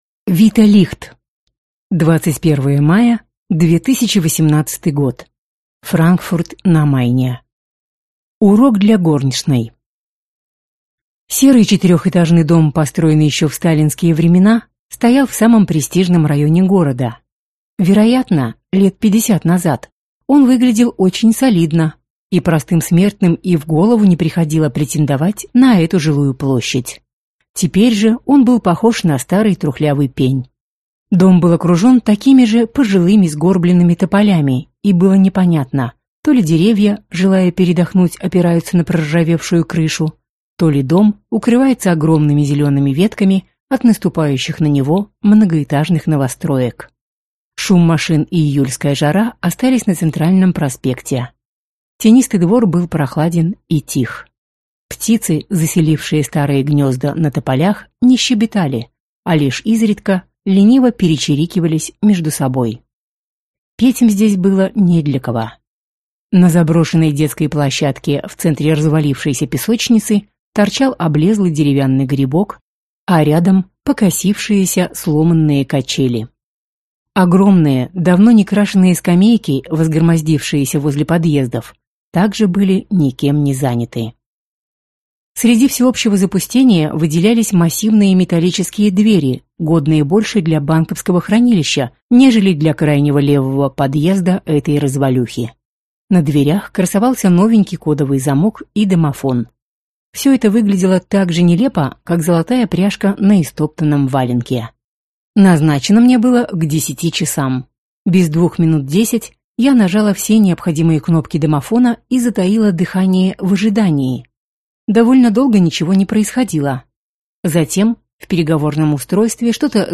Аудиокнига Урок для горничной | Библиотека аудиокниг